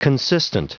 Prononciation du mot consistent en anglais (fichier audio)
Prononciation du mot : consistent